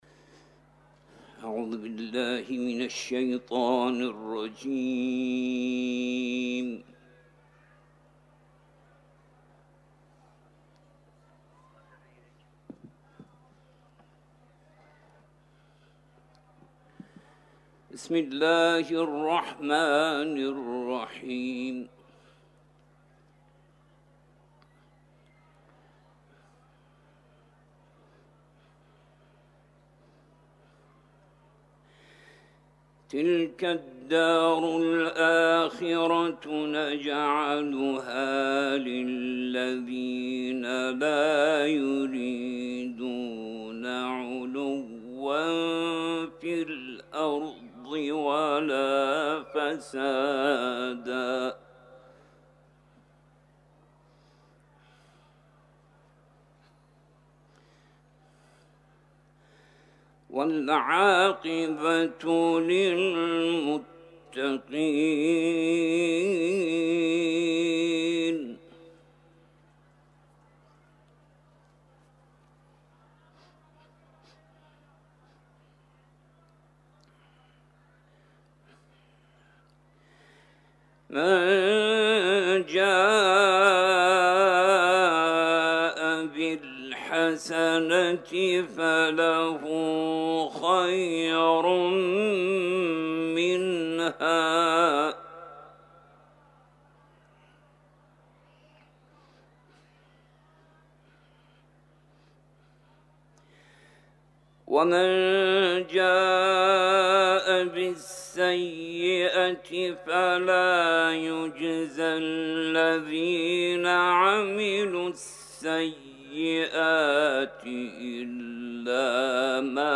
صوت | تلاوت